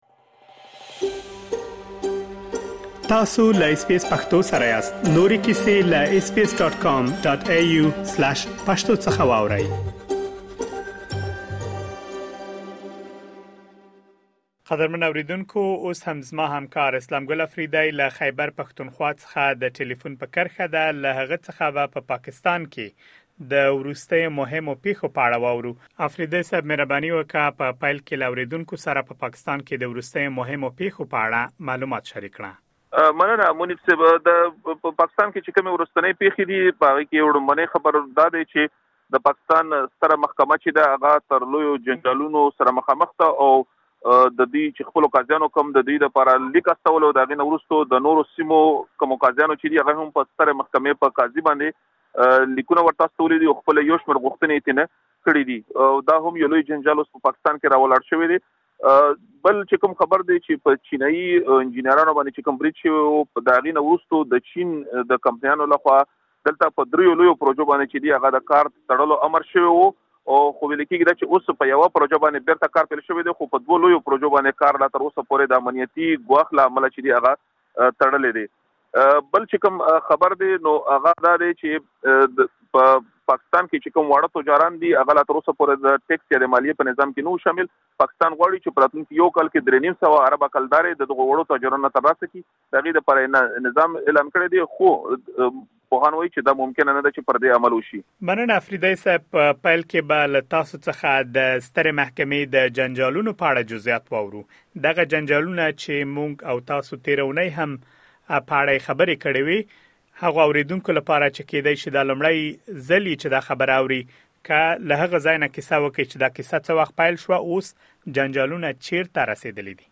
د پنځو چینایي انجینیرانو له وژل کېدو وروسته، چینایي شرکتونو په دغه هېواد کې د درې لویو پروژو کارونه ودرول، مګر وروستي راپورونه ښيي چې د یوې لویې پروژې کارونه بېرته پیل شوي. د پاکستان د وروستیو مهمو پېښو په اړه معلومات دلته په ترسره شوې مرکې کې اورېدلی شئ.